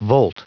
Prononciation du mot volt en anglais (fichier audio)
Prononciation du mot : volt